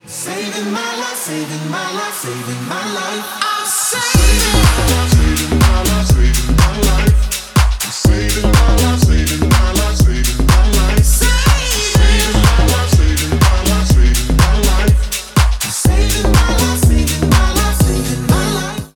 • Качество: 192, Stereo
мужской вокал
dance
house
Eurodance